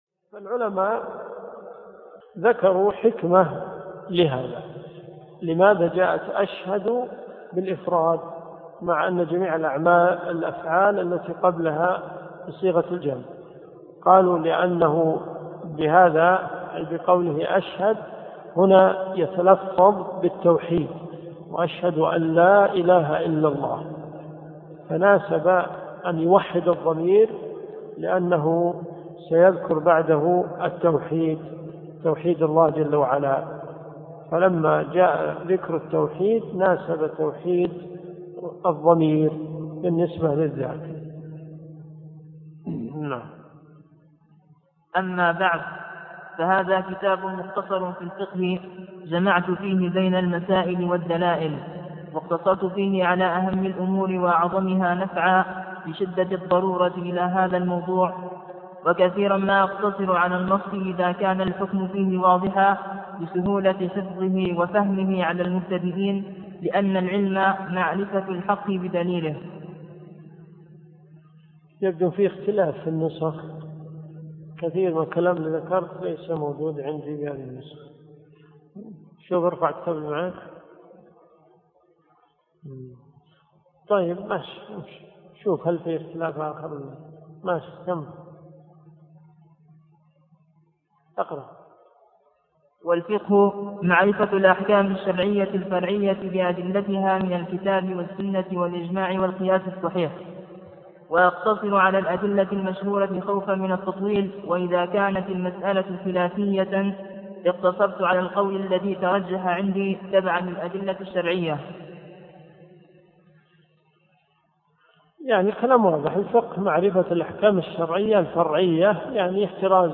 الدروس الشرعية